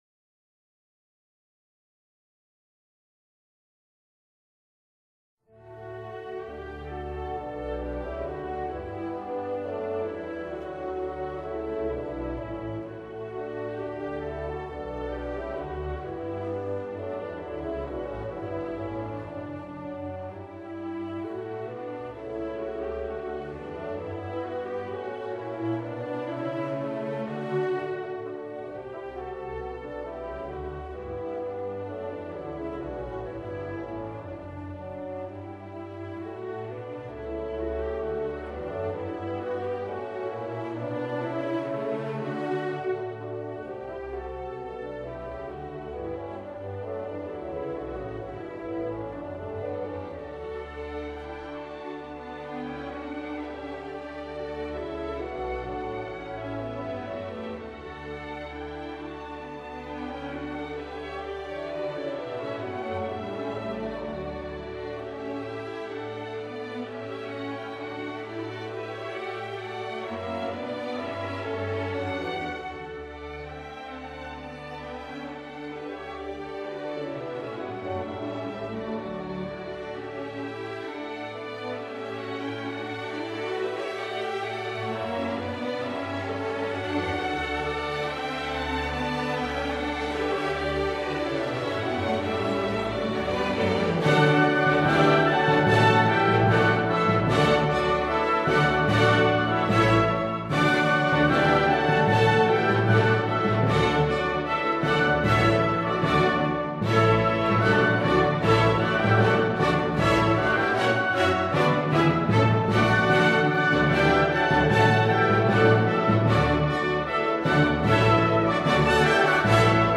موومان چهارم سمفونی 9 شامل اجرای کورس وکالی با متن «Ode to Joy» (سرود شادی) است.
• موومان های اول تا سوم: موسیقی بدون کلام و گاهی دراماتیک، گویی مسیر دشوار زندگی و چالش های انسان را روایت می کنند.
• موومان چهارم: ورود کر و سولوها، گویی نور امید در تاریکی ظاهر می شود و همه را به شادی و برادری دعوت می کند.